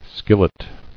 [skil·let]